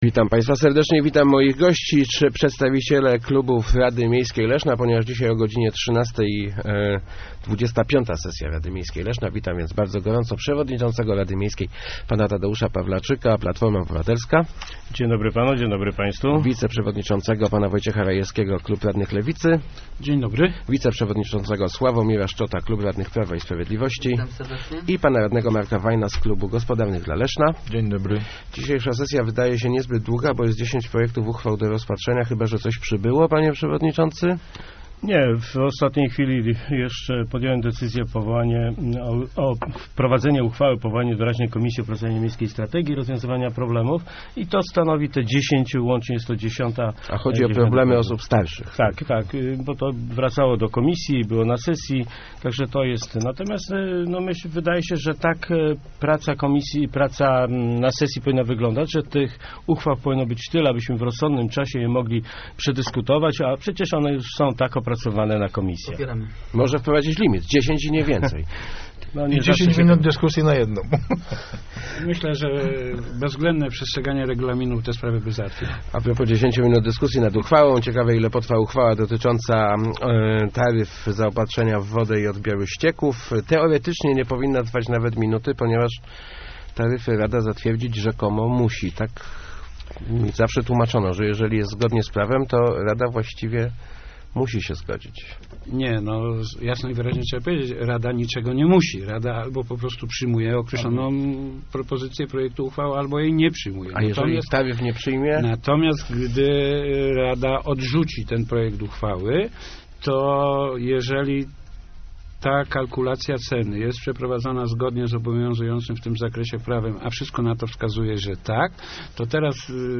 Mamy najdroższą wodę w regionie - mówił w Rozmowach Elki Sławomir Szczot, przewodniczący PiS w Radzie Miejskiej Leszna. Podczas sesji radni mają przyjąć nowe taryfy zaopatrzenia w wodę i odbiór ścieków.